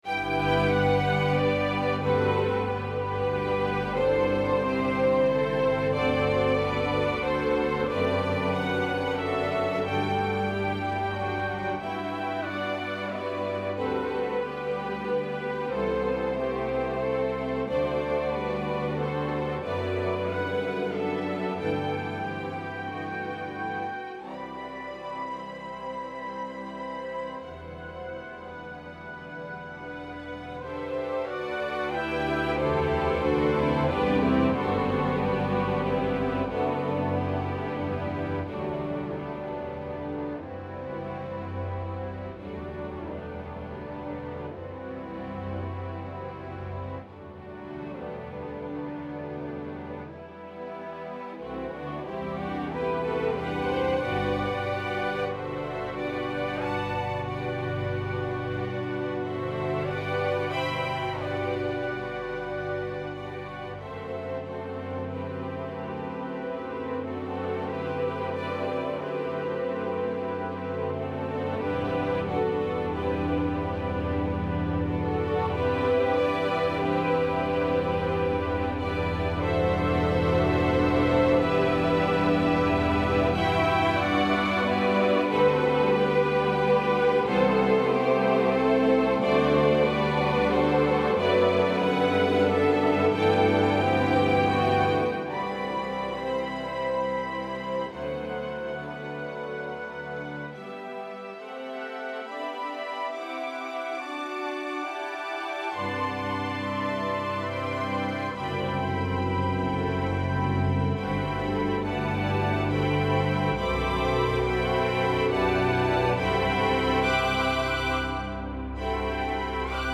in Orchestral and Large Ensemble
This is a simple expression of hope.